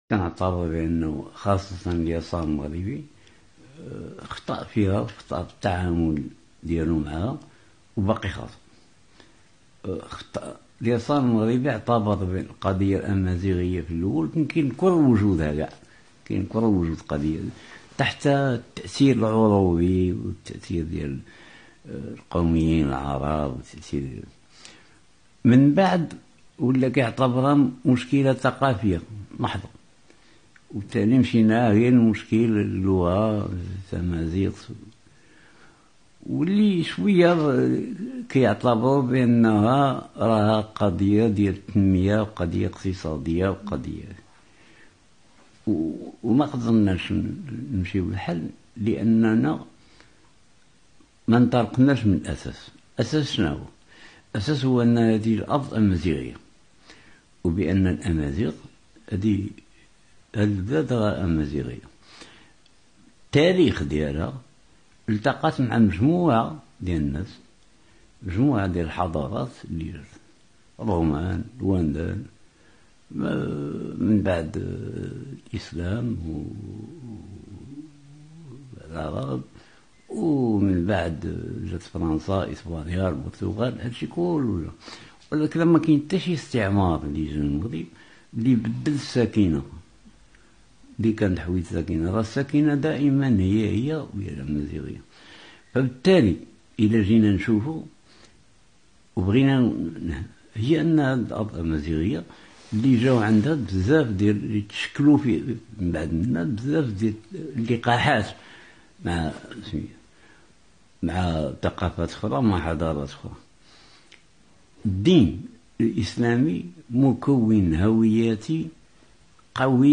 In een interview aan het einde van zijn leven heeft hij erkend dat links in Marokko een fout heeft gemakt door de amazigh identiteit van het land te verzwijgen. Een korte samenvatting van het interview (audio) .